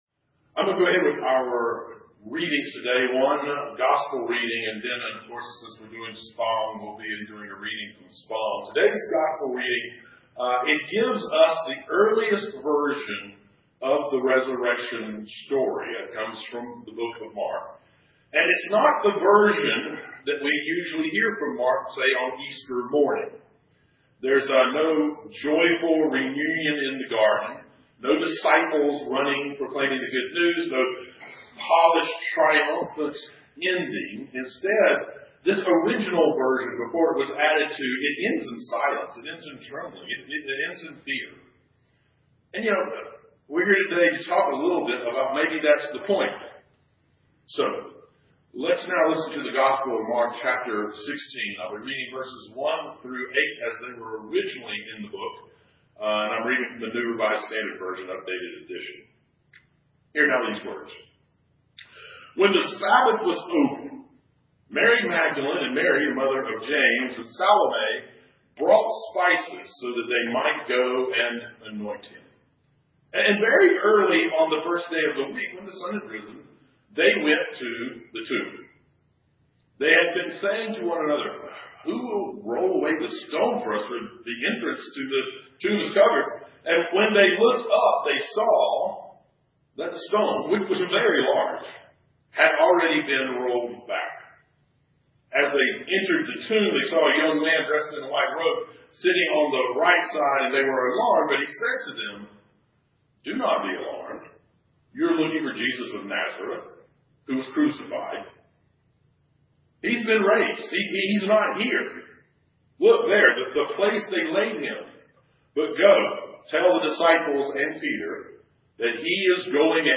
Sermon Series: What if?